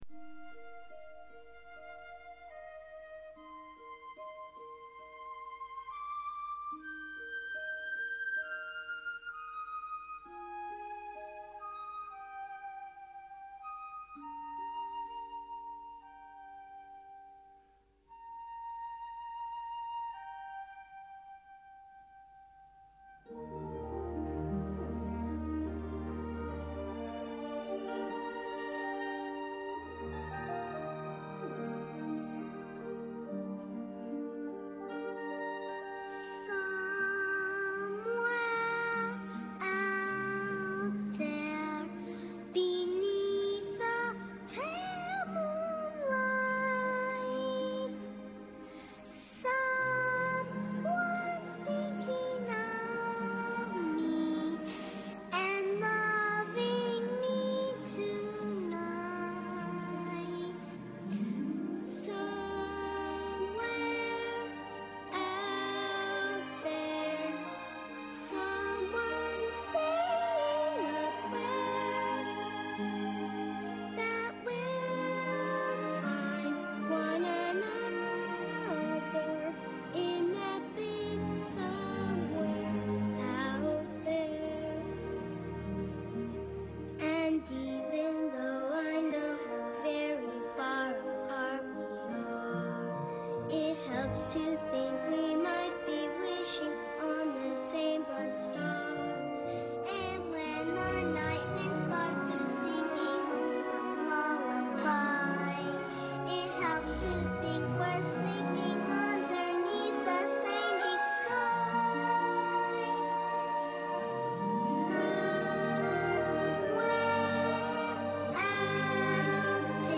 Voiced by child actors